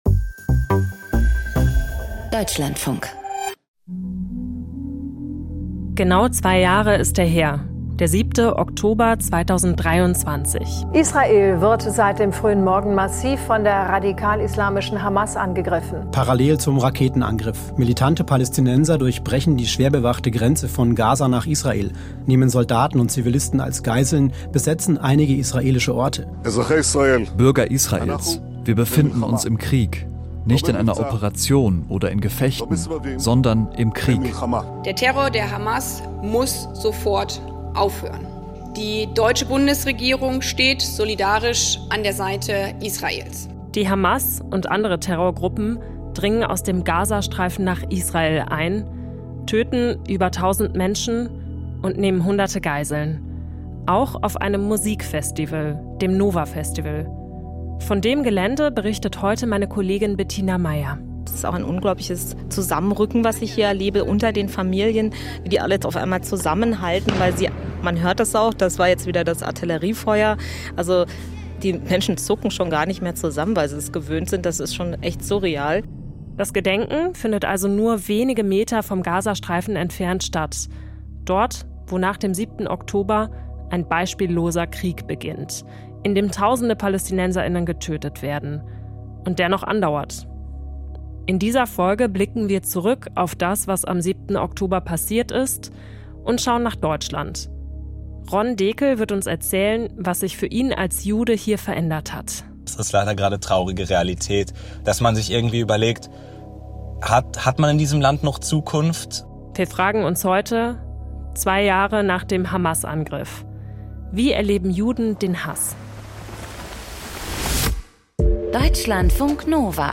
Am 7. Oktober 2023 hat die islamistische Terrororganisation Hamas Israel angegriffen. Überlebende und Angehörige von Geiseln erzählen, was sie an diesem Tag erlebt haben und wie der Terrorangriff alles verändert hat.